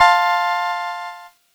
Cheese Chord 11-F3.wav